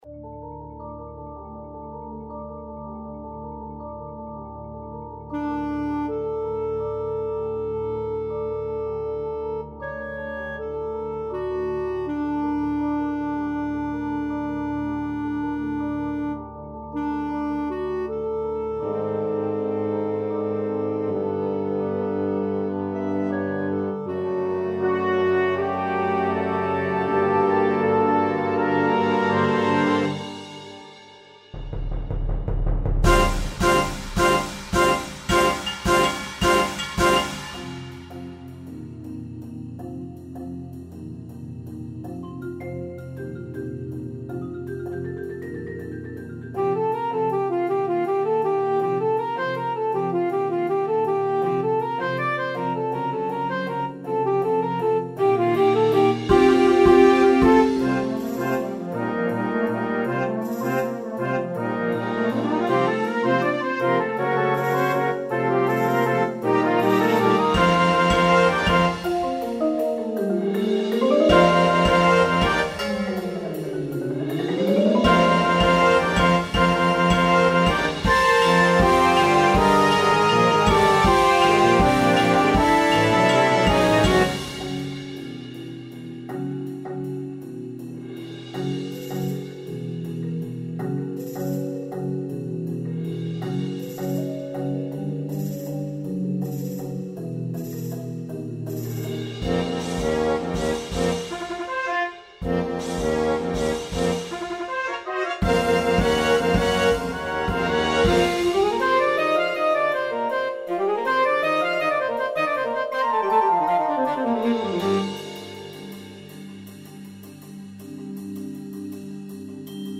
See it Live!